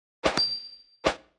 Sfx_Anim_Base_Tankgirl.wav